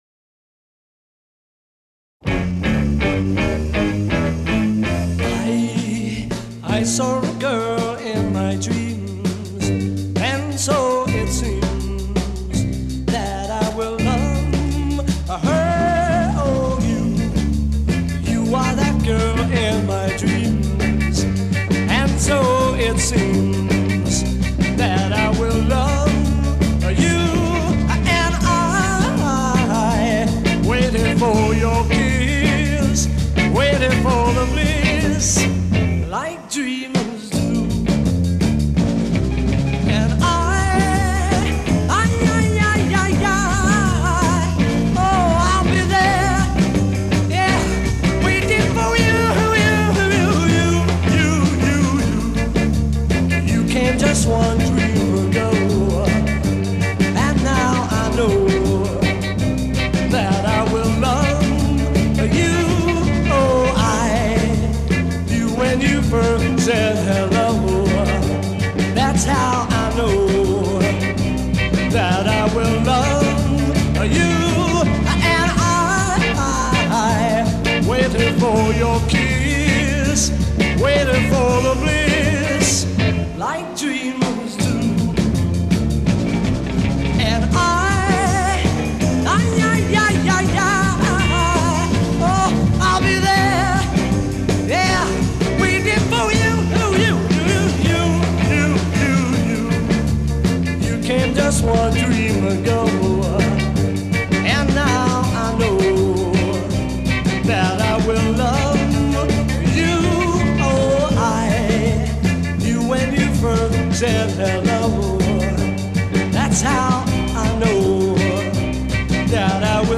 There were no rough edges.